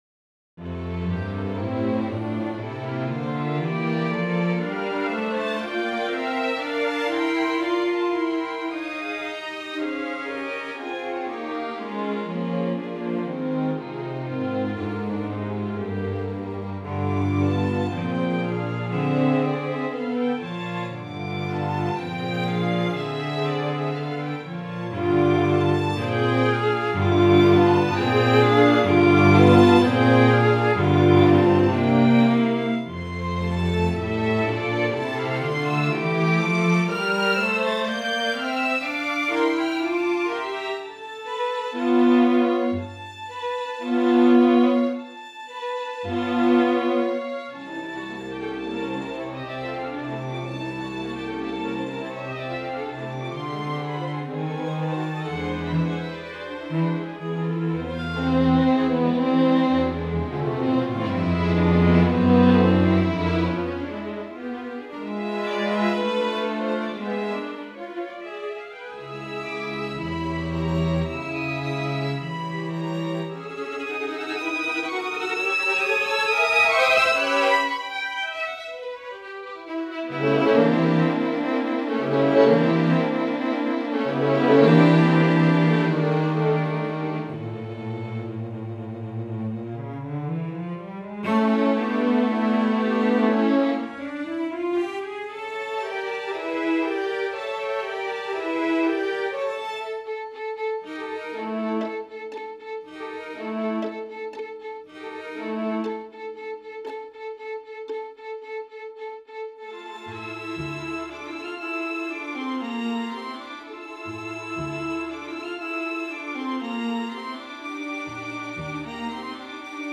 Just a raw mockup to try the sounds.
Is the rest of the chamber orchestra still too evident? Maybe, mostly for the exaggerated distance between the soloists?
It's Elite Strings, Close mics only, artificial reverb added.
In any case, there is some room in the Close mics.
All considered, I'm not totally convinced that it wouldn't work as a string quartet.
Unfortunately you can hear something of the groups now and than to which the soloists belong.